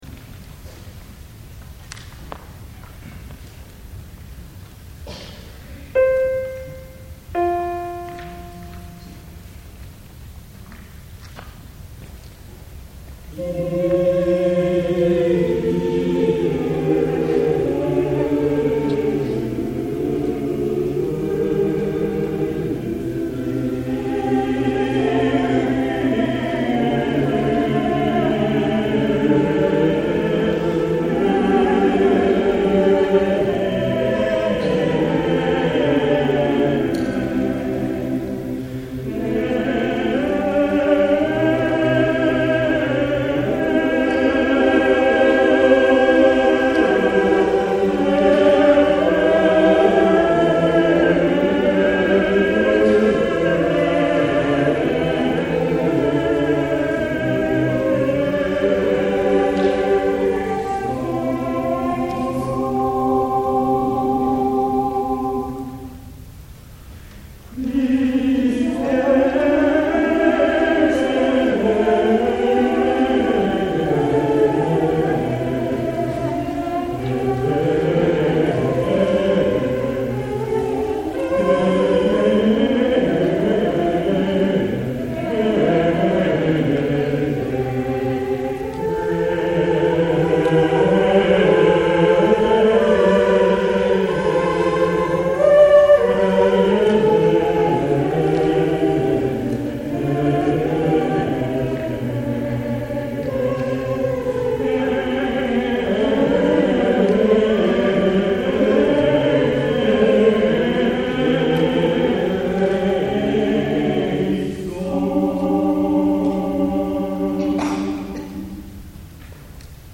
神奈川県相模原市で、アカペラのアンサンブルを楽しんでいます。
中世・ルネッサンスのポリフォニーの曲を中心に歌っています。
相模原市の合唱祭に出演しました
(相模女子大学グリーンホール大ホール)